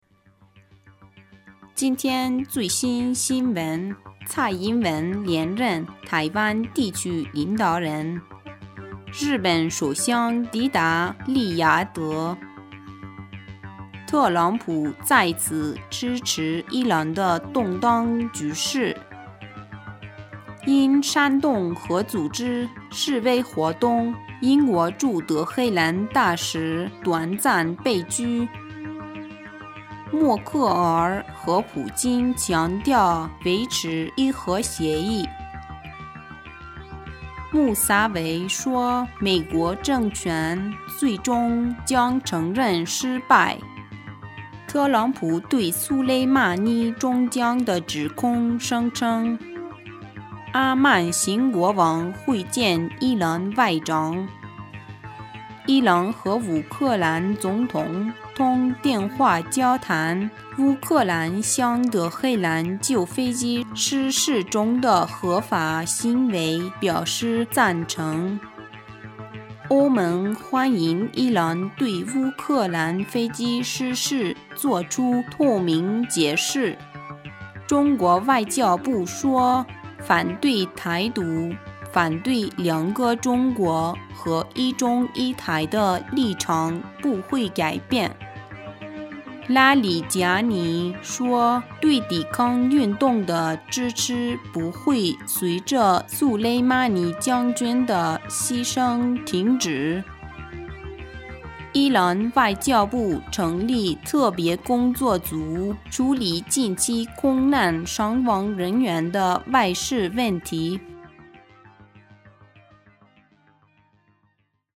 新闻